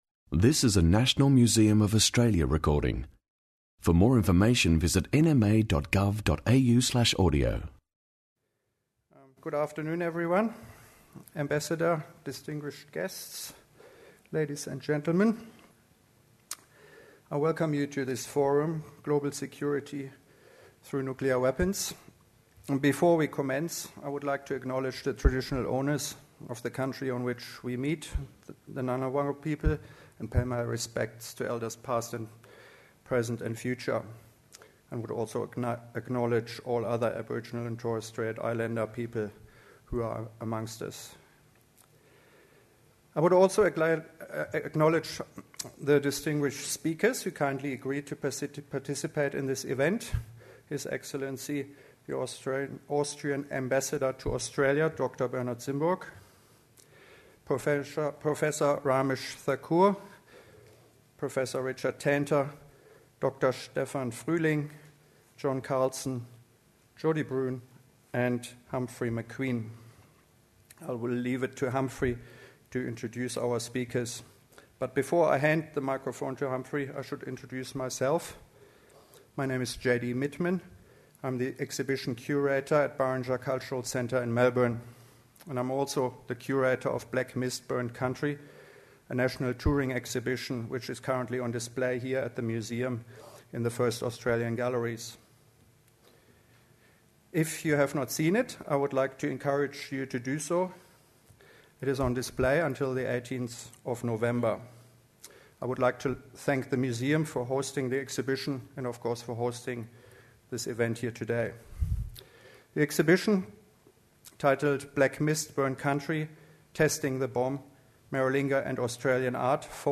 Expert panellists examine the current situation on global security.